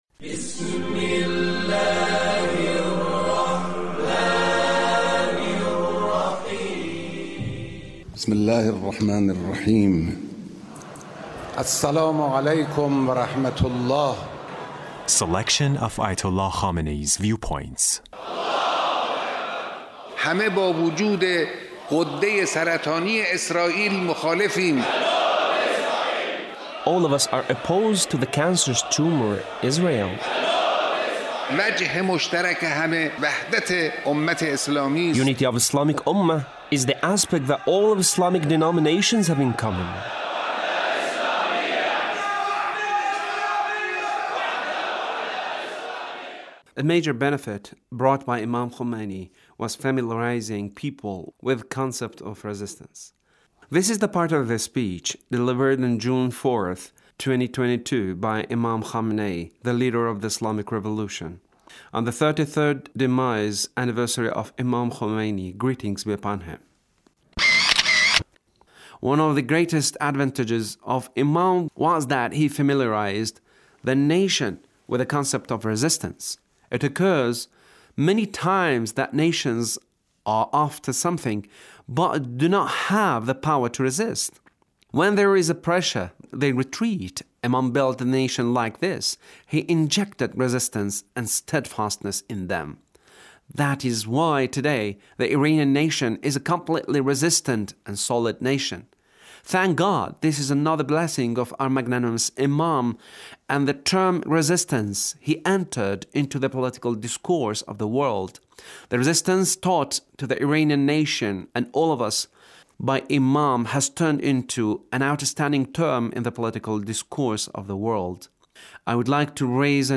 Leader's speech